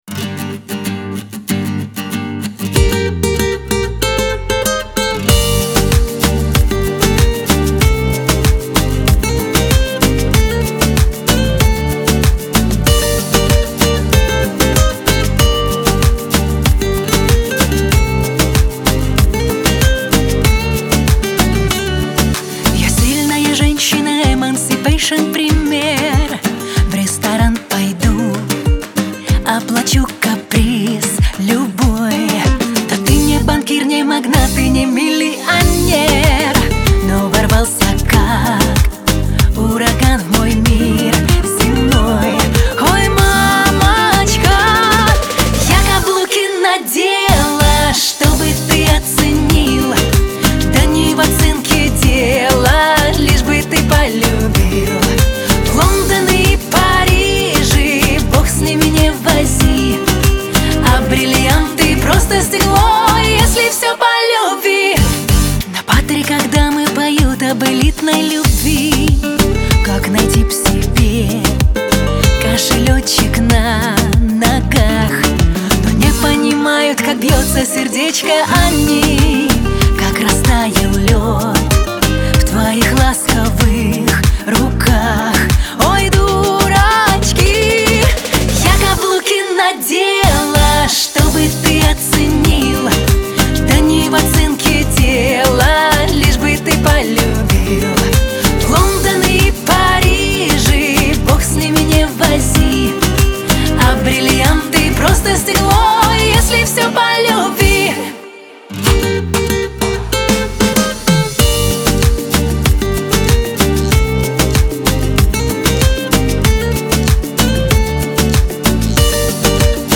диско
эстрада , pop